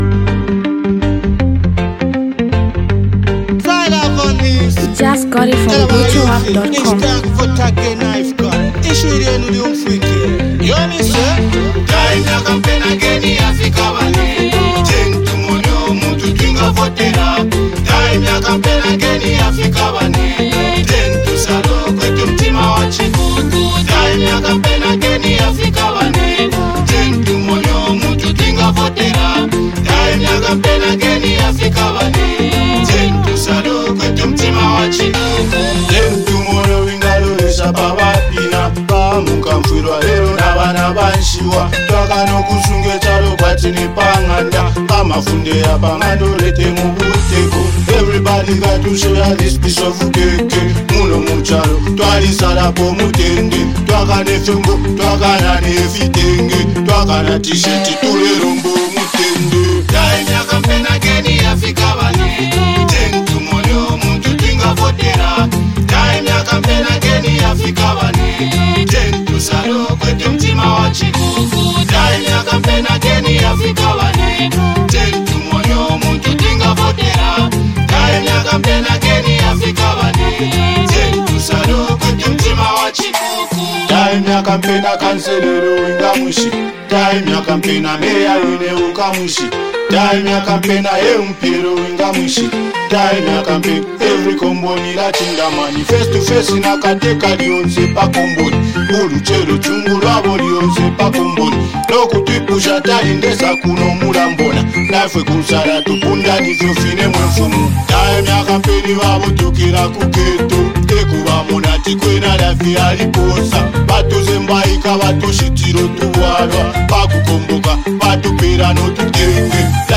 rising up talented reggae star.
powerful campaigning hit song